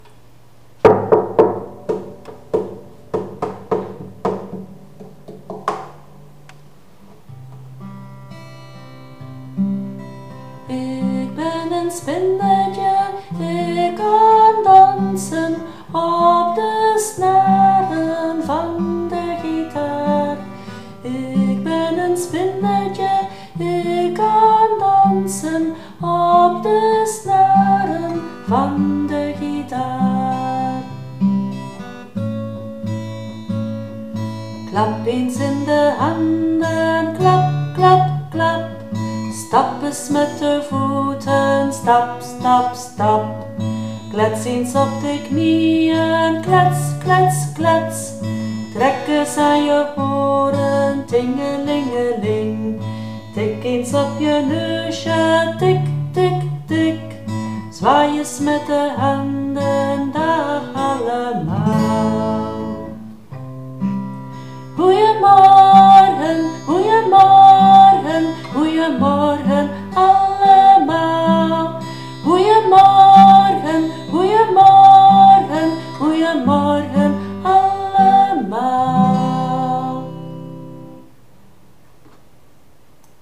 en dan speel ik op de gitaar.